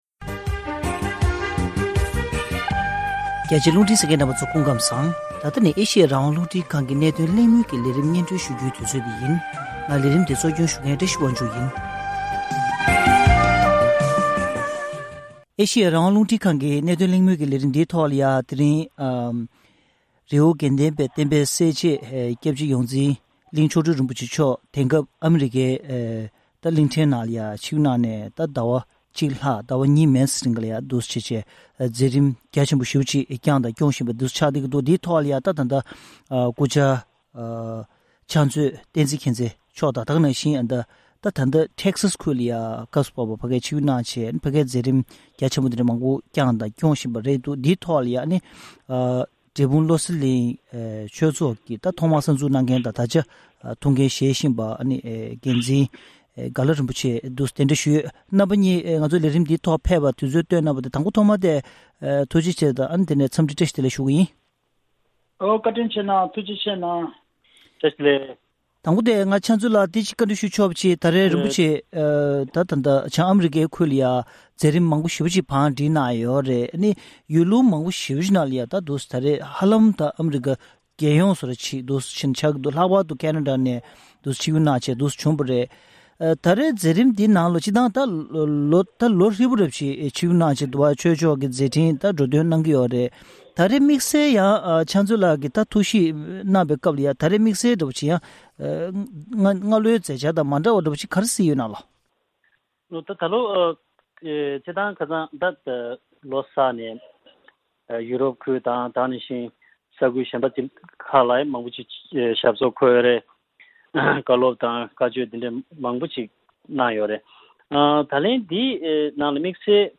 ཐེངས་འདིའི་གནད་དོན་གླེང་མོལ་གྱི་ལས་རིམ་ནང་།